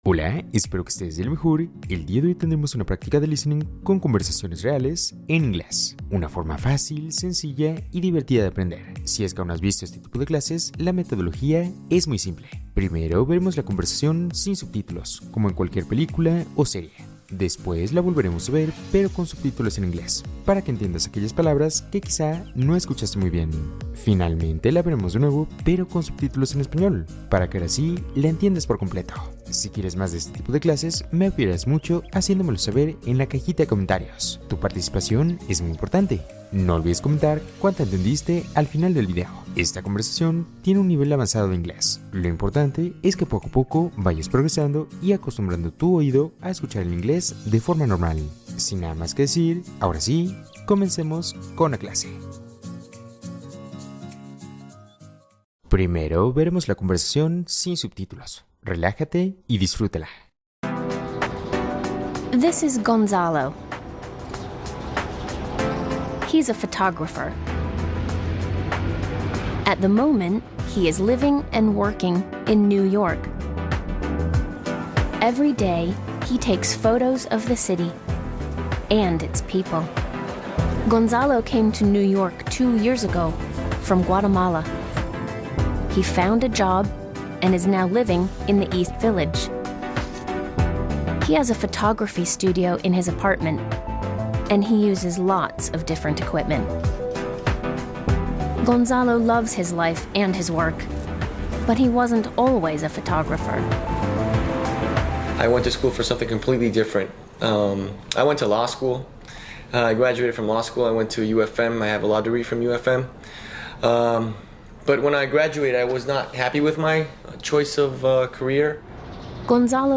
Ejercicio de escucha para mejorar vocabulario y comprensión